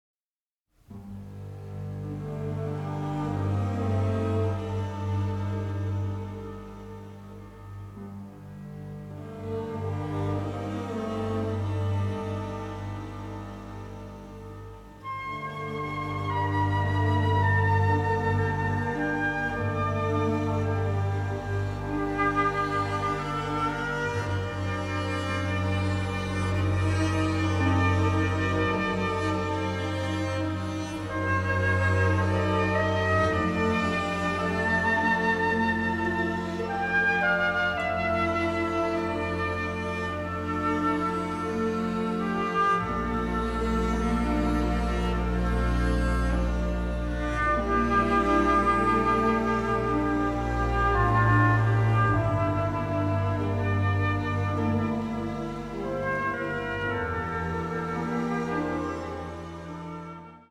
deeply delicate score